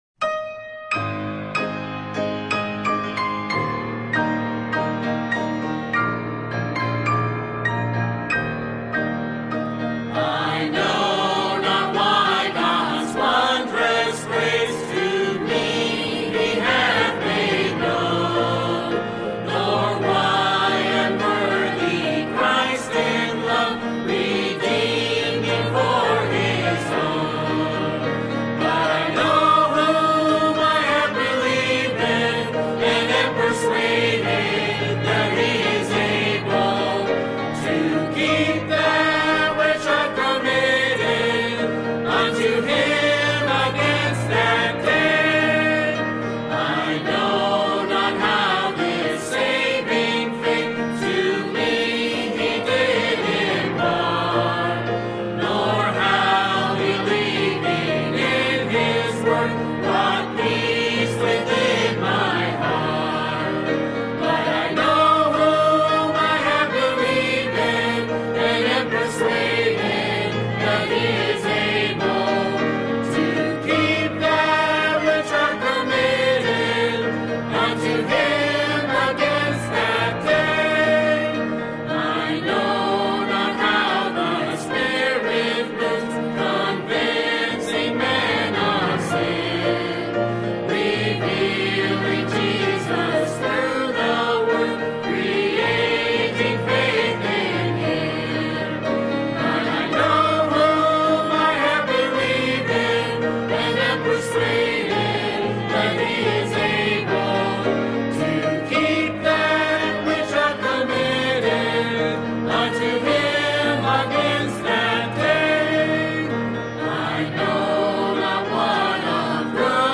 Music Hymns